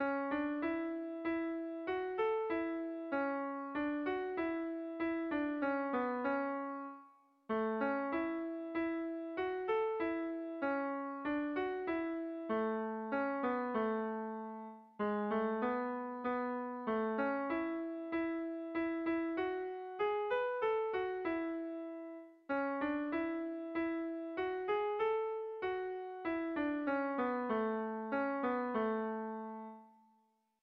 Kontakizunezkoa
Zortziko ertaina (hg) / Lau puntuko ertaina (ip)
A1A2BD